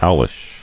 [owl·ish]